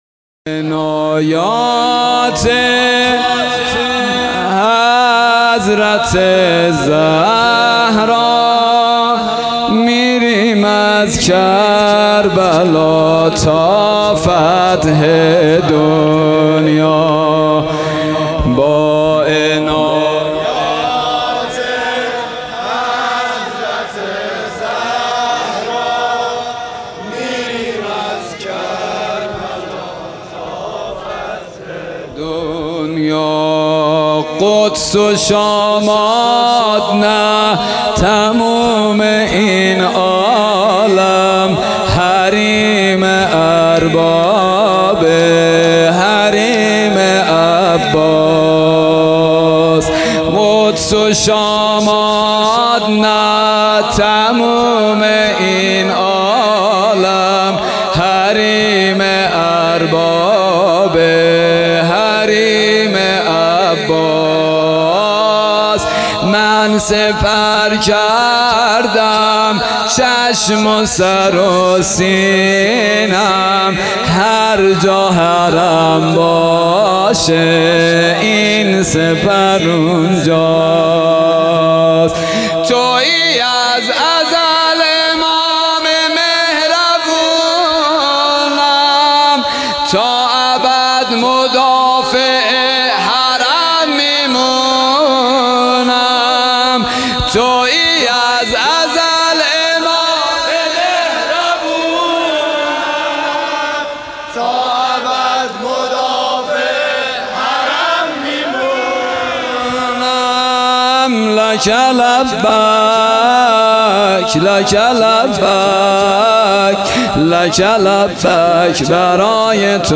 شب هشتم محرم الحرام 1443